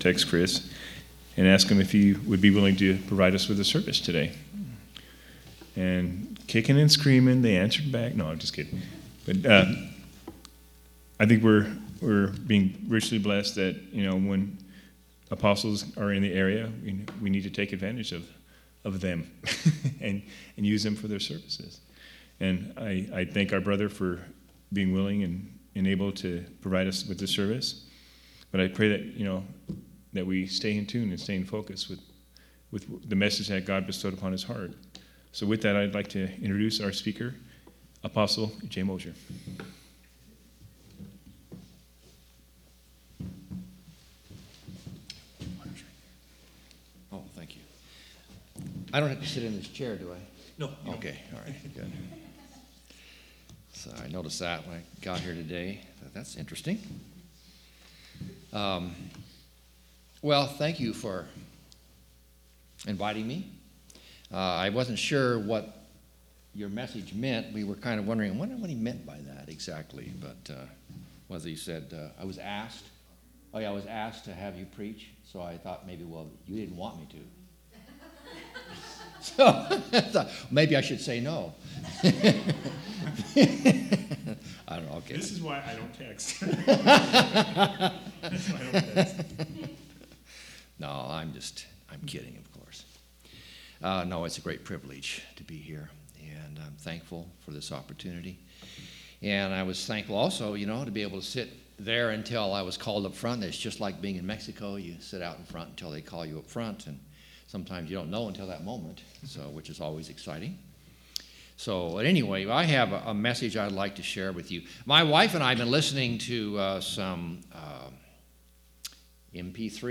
8/20/2017 Location: Treasure Valley Local Event